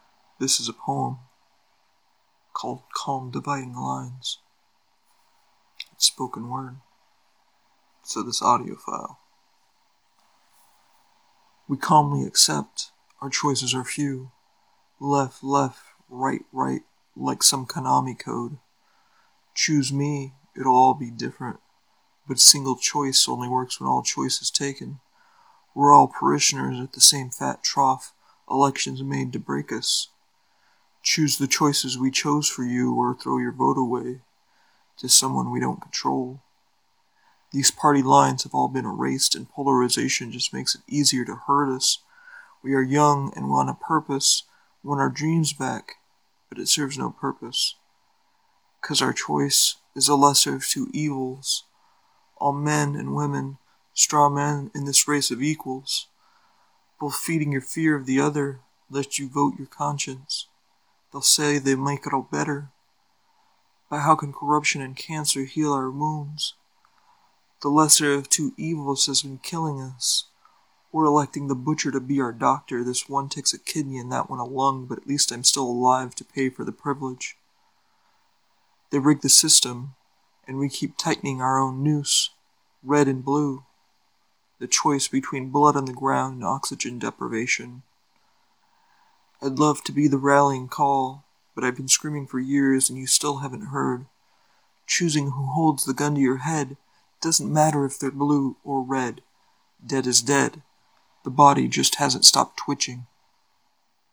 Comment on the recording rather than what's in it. There is a audio file with this spoken as it was intended.